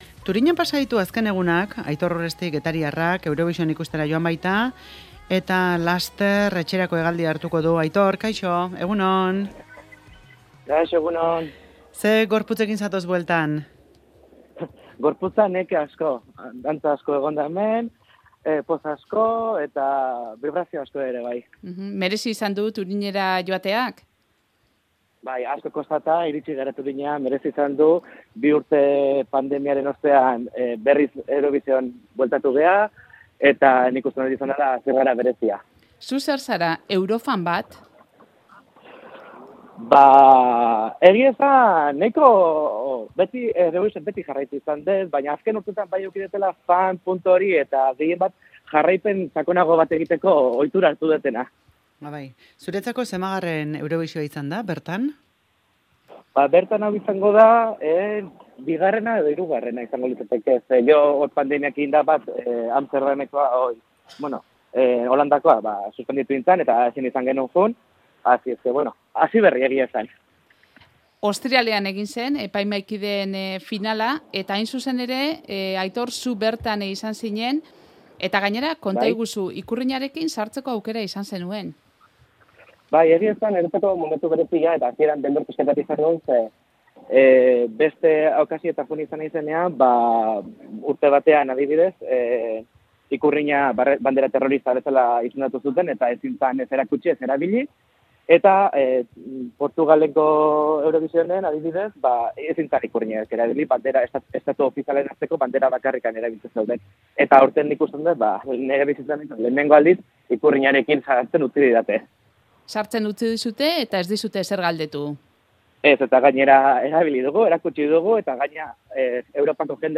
elkarrizketa egin diogu Eurovisionen inguruan